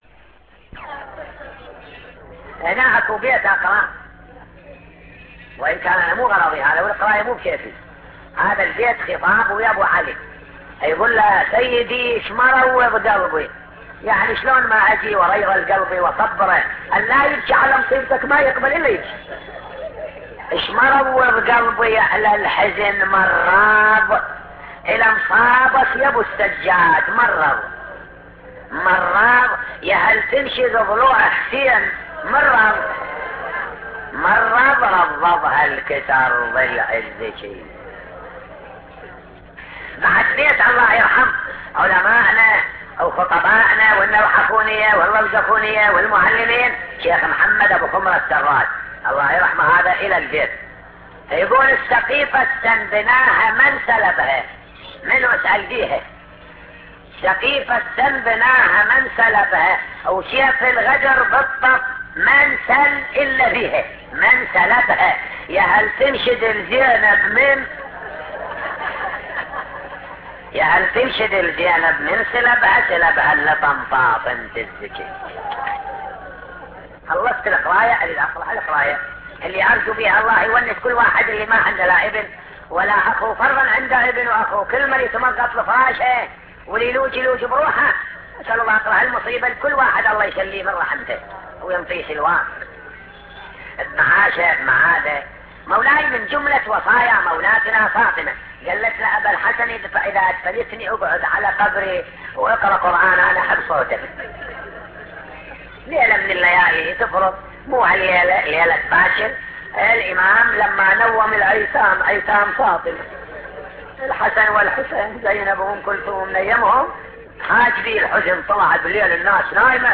نواعي حسينية 3